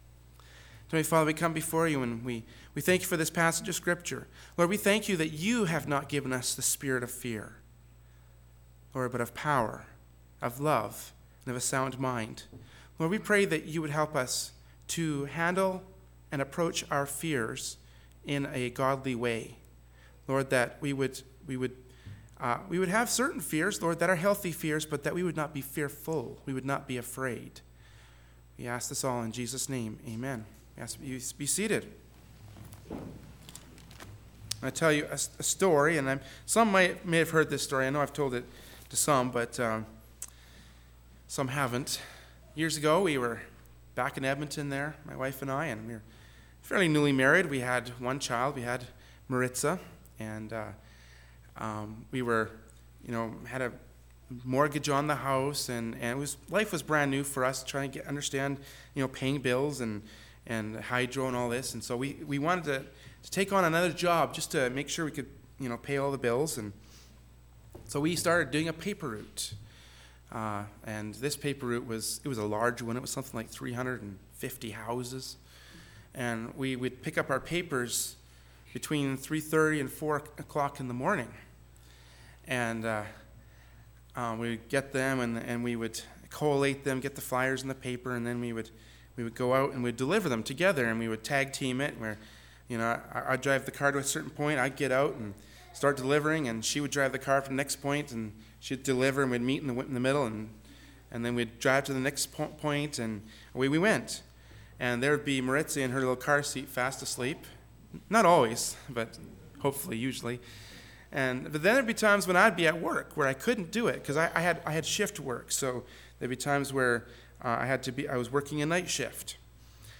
“Is your God big enough?” from Sunday Morning Worship Service by Berean Baptist Church.